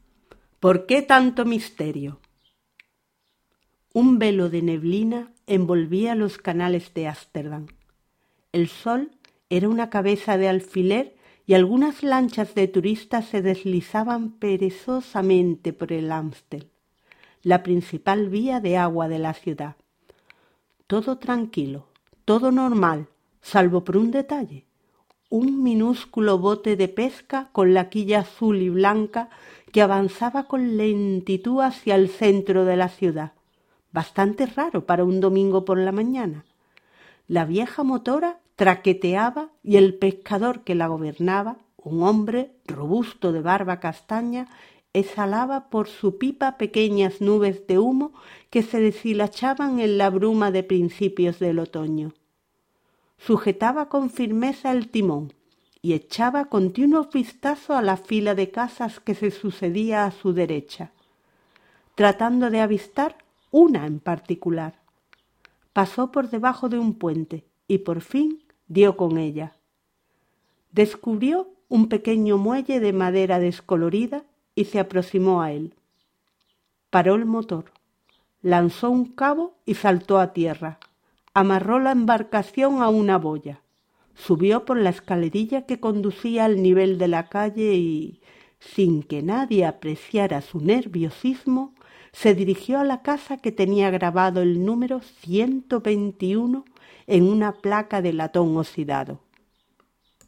PREPARAMOS UNA LECTURA EN VOZ ALTA | ¿CÓMO SE LEE UN TEXTO EN VOZ ALTA?